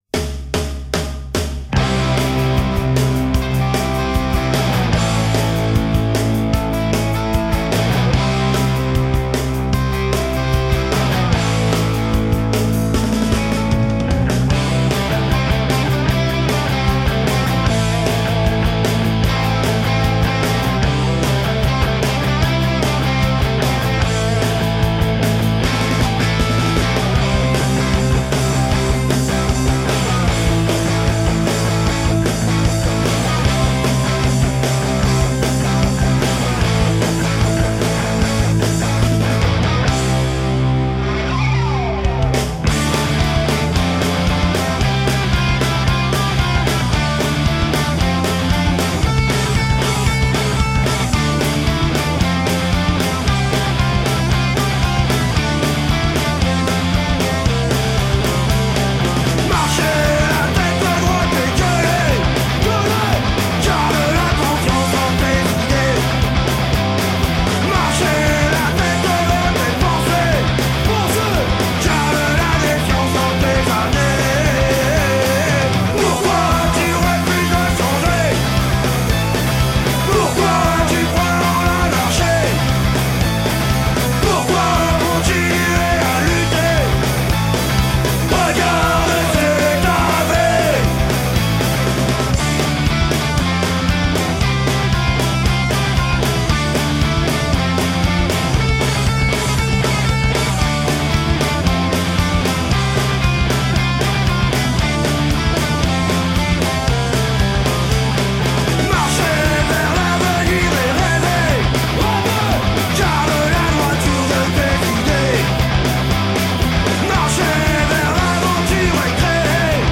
du Punk Rock politisé avec une petite touche Rock'n'Roll
Punk Rockers